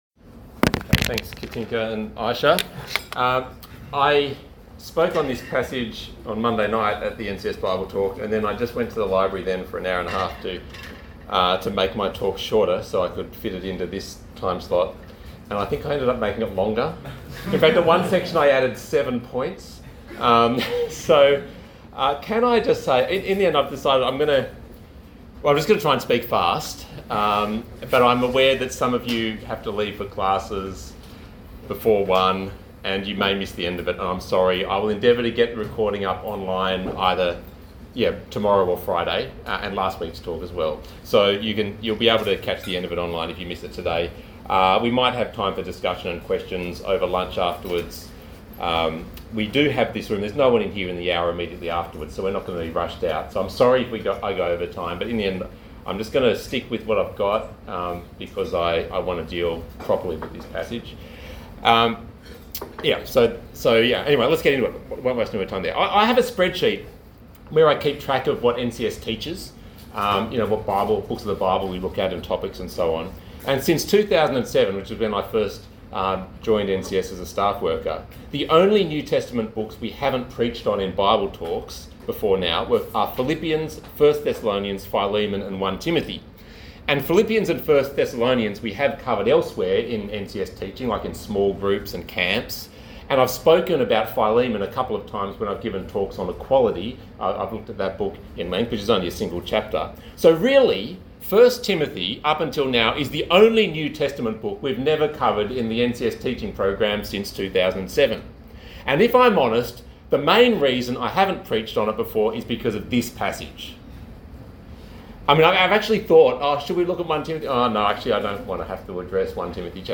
Talk Type: Bible Talk Topics: church , leadership , ministry , Prayer